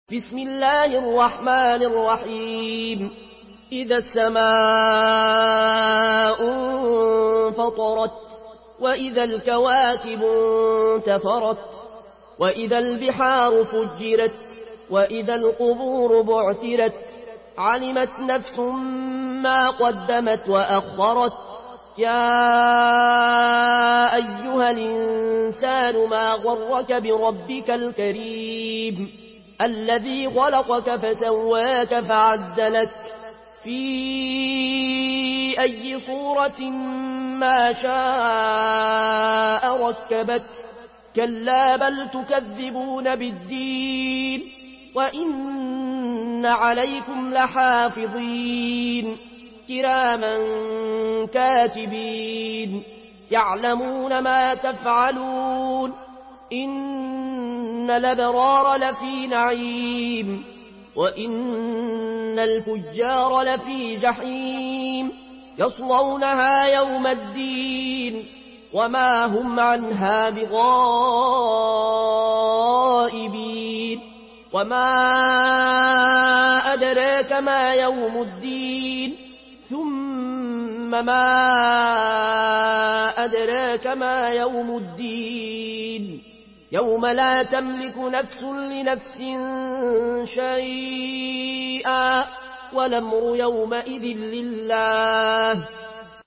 Une récitation touchante et belle des versets coraniques par la narration Warsh An Nafi From Al-Azraq way.
Murattal